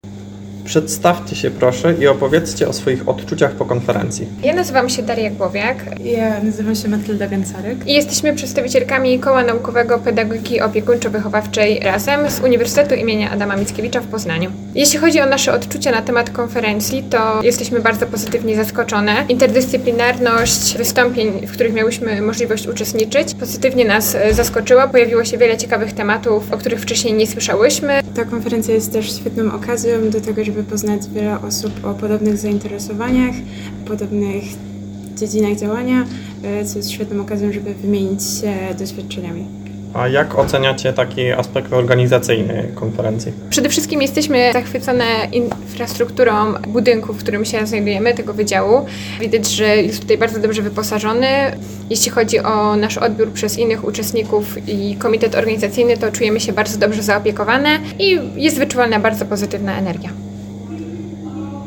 Tak na temat konferencji wypowiedziały się prelegentki z Poznania: